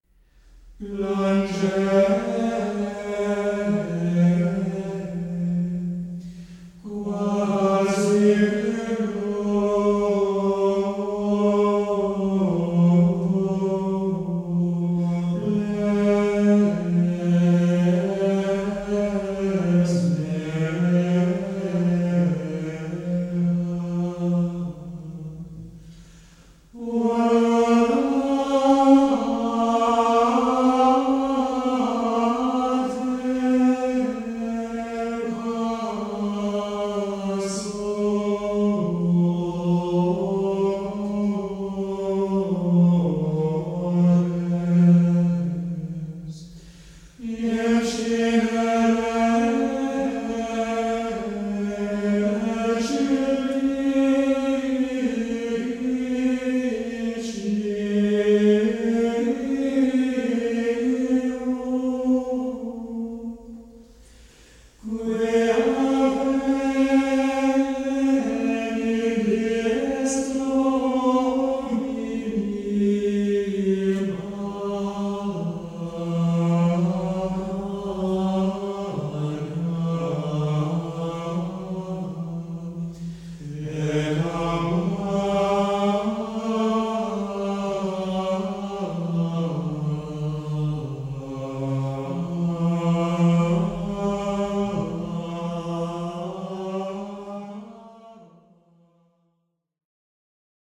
CD-Quality, Windows Media (WMA) Format